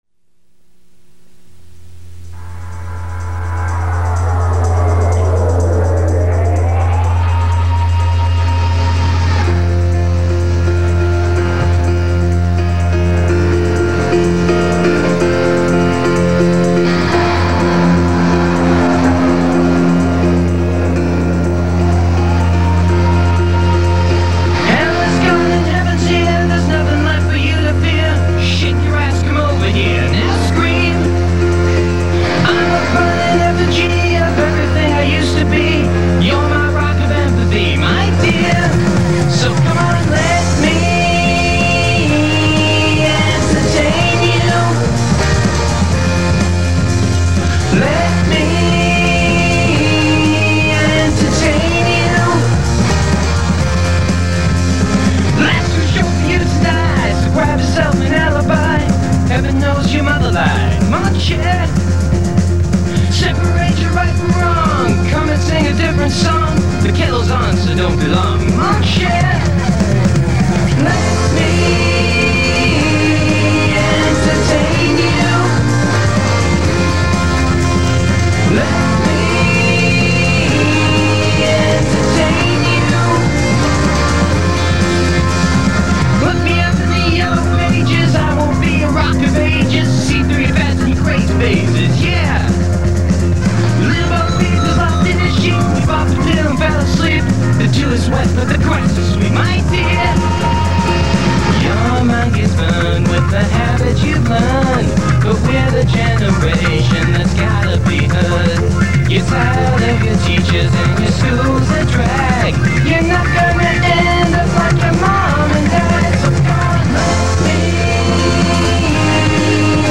Music Video/Comedy
The backup singers